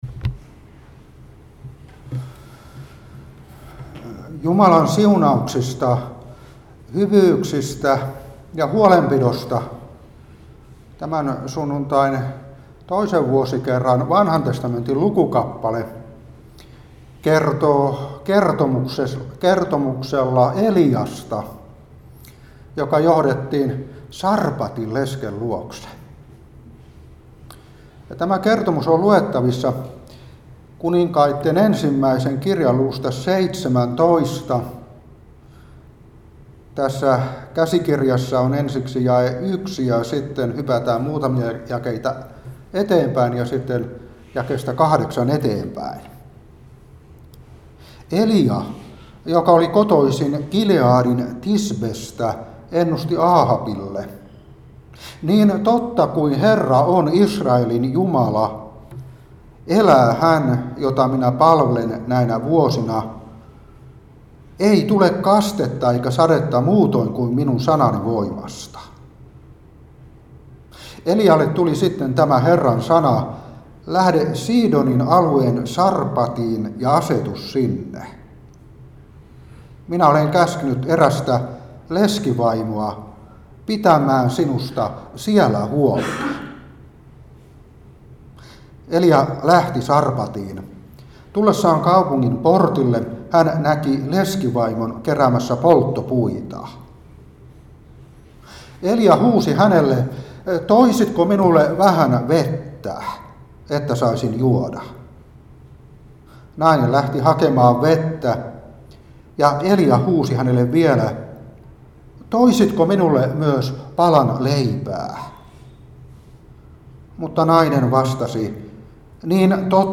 Seurapuhe 2021-9. 1.Kun.17:1,8-16.